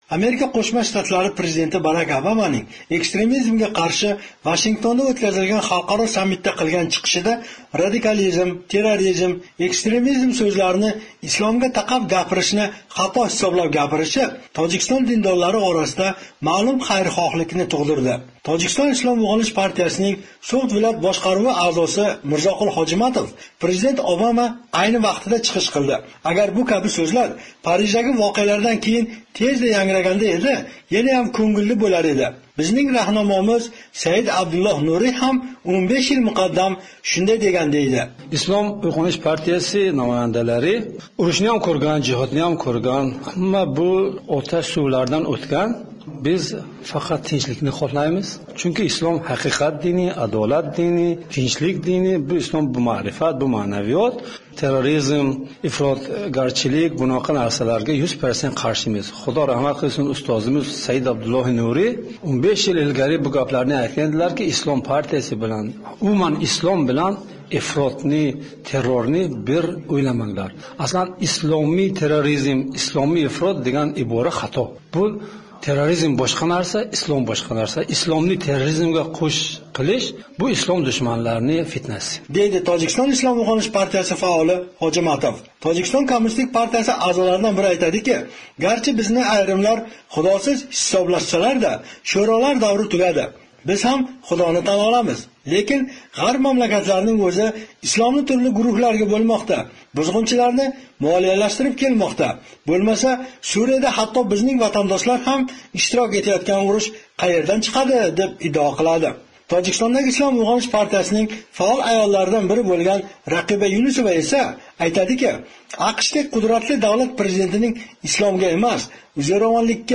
Xo'jand, Tojikiston
reportaji